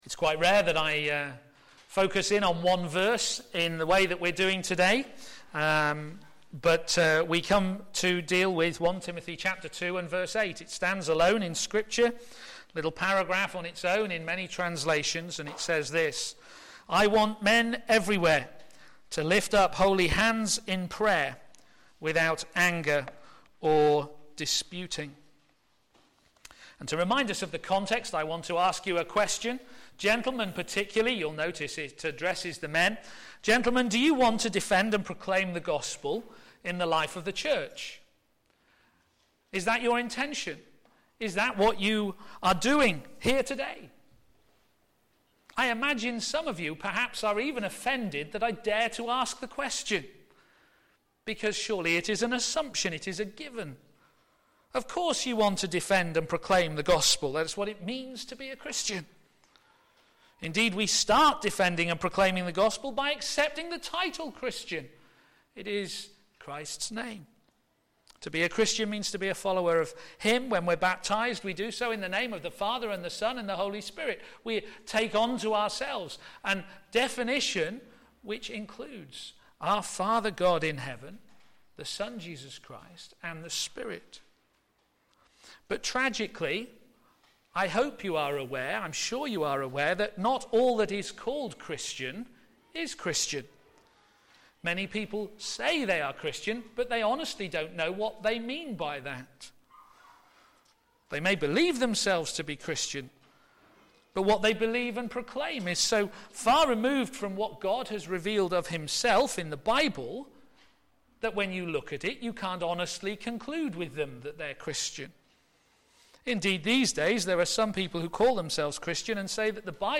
Media for a.m. Service
Theme: The Church - It's Members the Men Sermon